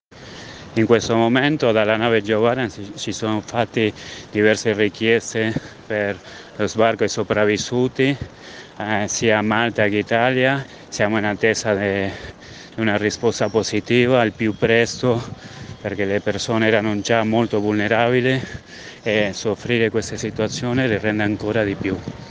vocale_geo_barents.mp3